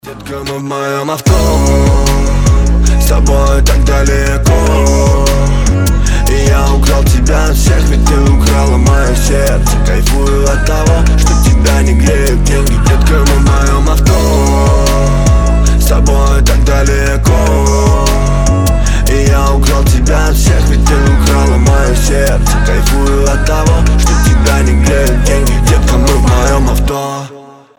• Качество: 320, Stereo
ритмичные
качающие